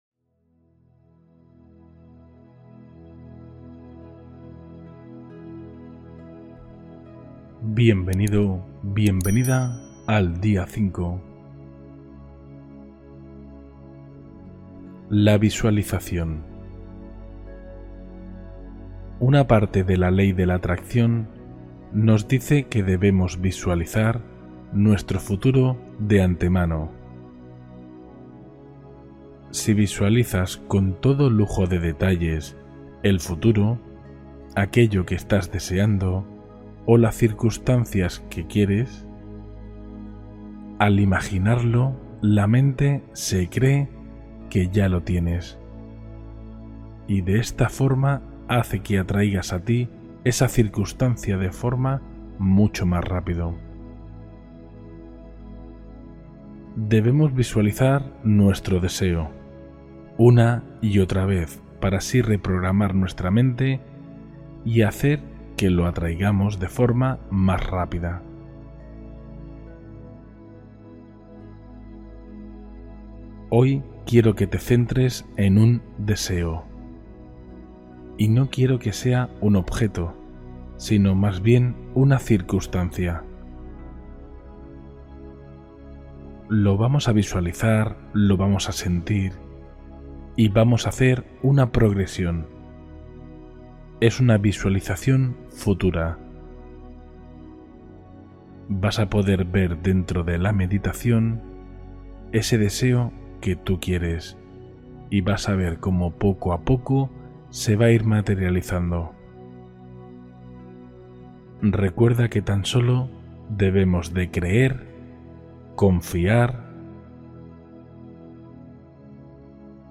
Visualización Guiada para Explorar Direcciones de Vida